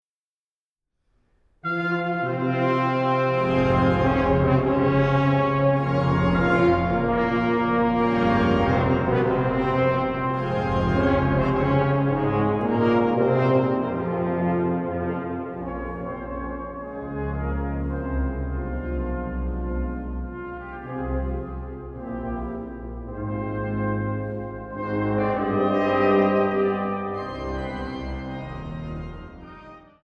CD 2: Schwerin, Dom St. Marien und St. Johannis
für Blechbläser und Orgel